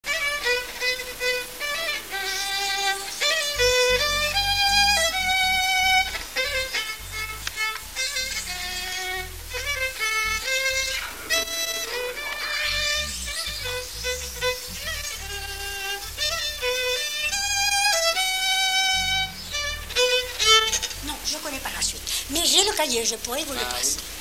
Marche
Instrumental
gestuel : à marcher
Pièce musicale inédite